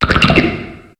Cri de Nodulithe dans Pokémon HOME.